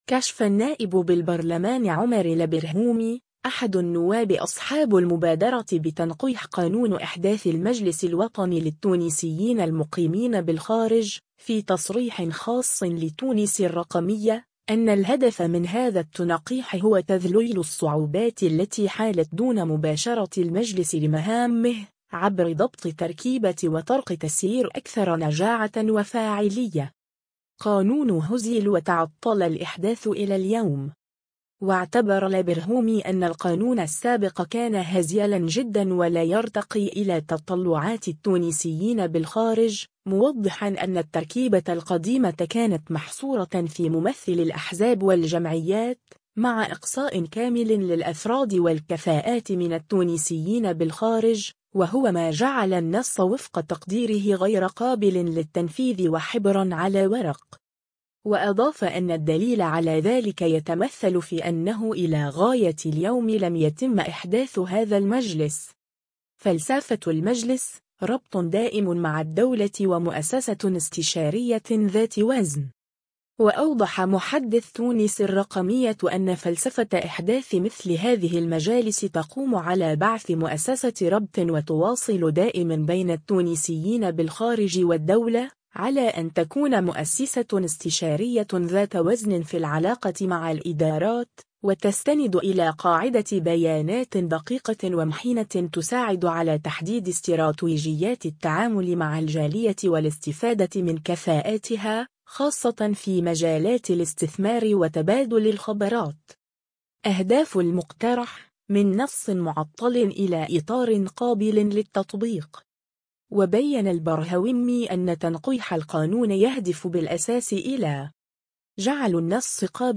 كشف النائب بالبرلمان عمر البرهومي، أحد النواب أصحاب المبادرة بتنقيح قانون إحداث المجلس الوطني للتونسيين المقيمين بالخارج، في تصريح خاص لـ”تونس الرقمية”، أن الهدف من هذا التنقيح هو تذليل الصعوبات التي حالت دون مباشرة المجلس لمهامه، عبر ضبط تركيبة وطرق تسيير أكثر نجاعة وفاعلية.